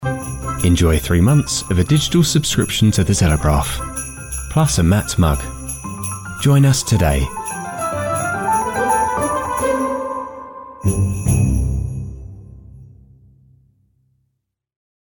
Social Ad – The Telegraph
BRITISH MALE VOICE-OVER ARTIST
Warm, neutral (non-regional) English accent
Telegraph-Voiceover.mp3